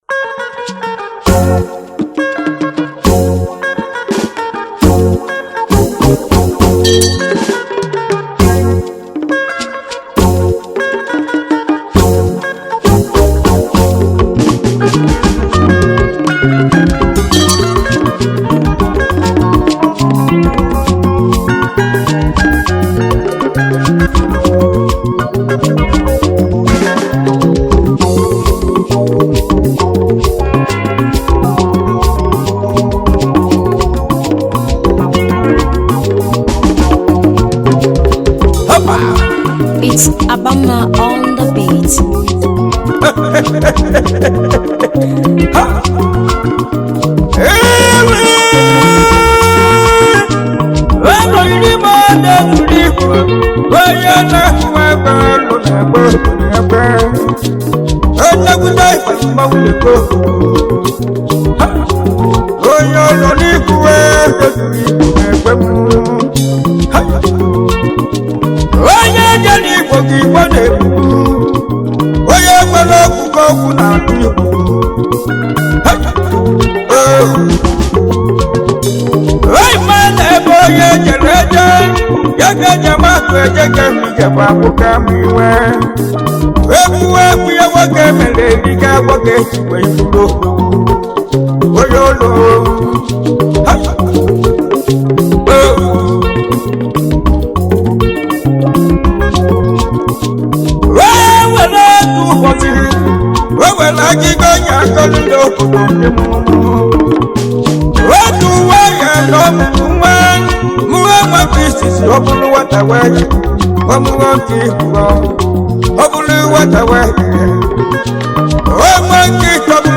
a good highlife tune